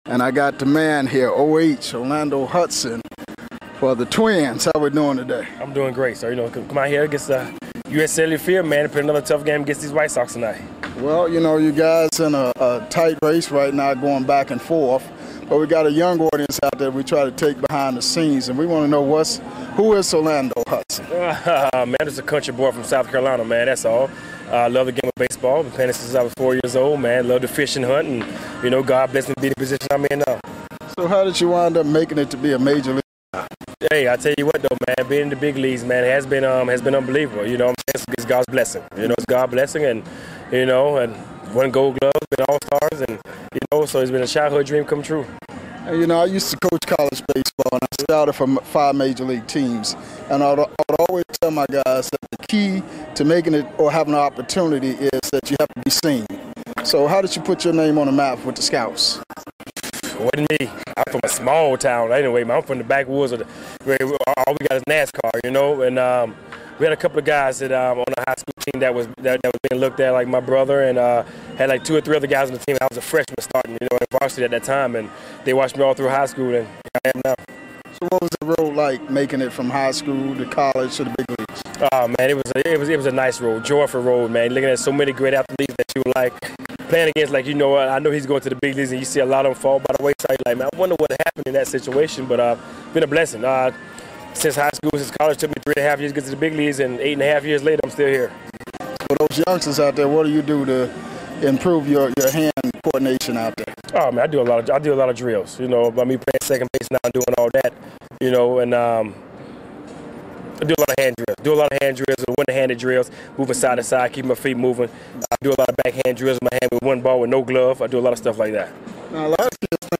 full uncut classic MLB interviews